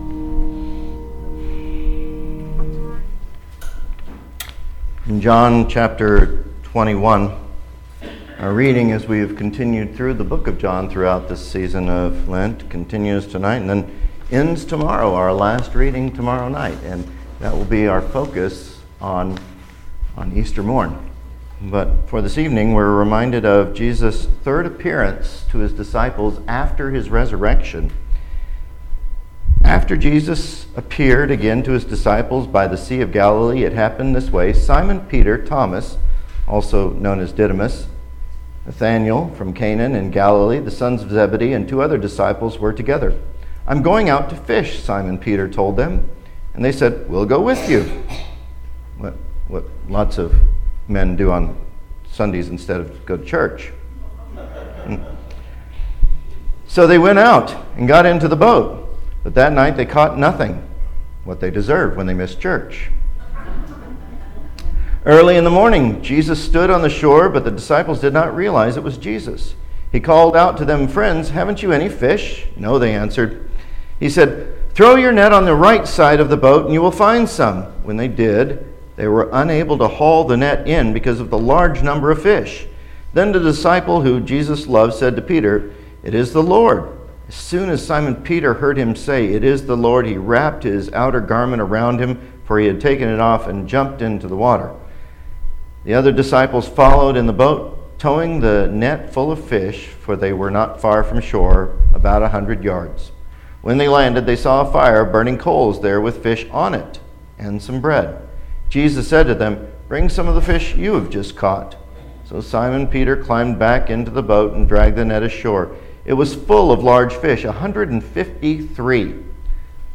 Sermons - Holy Cross Lutheran Church